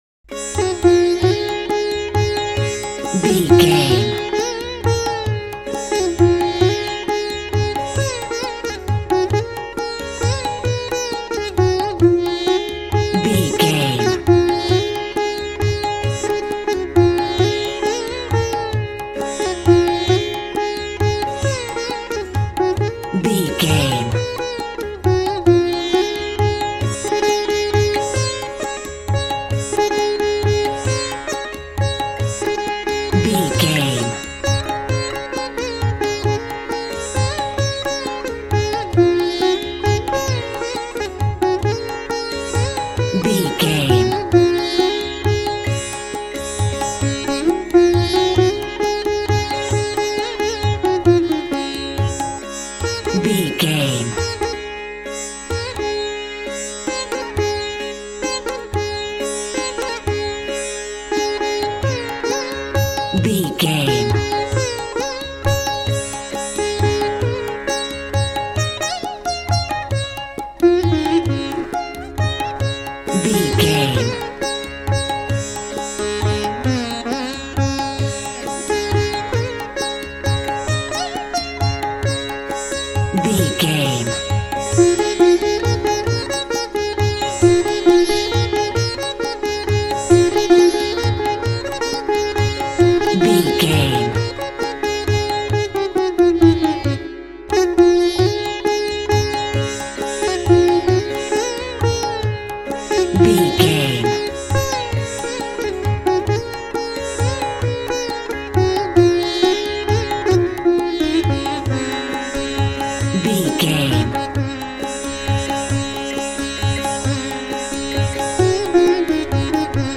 Mixolydian
D♭
World Music